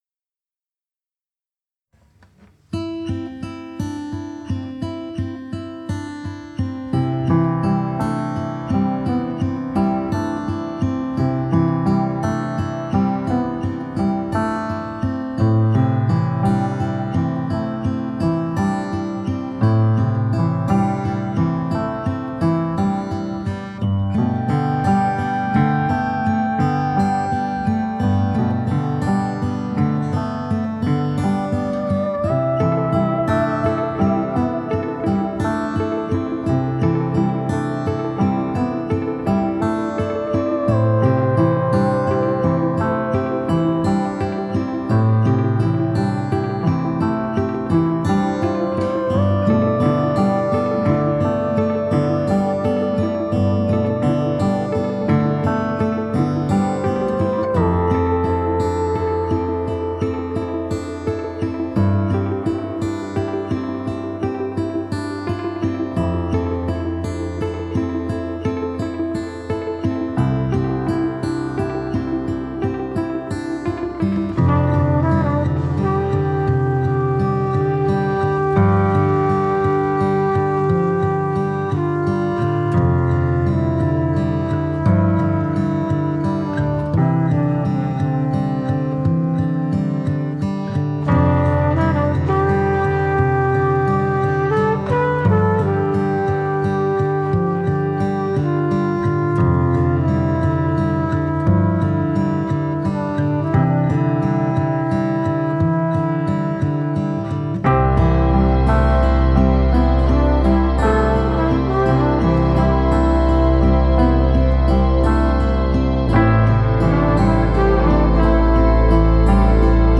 le duo suédois
pop folk instrumentale. Trompette et synthés
la guitare et la piano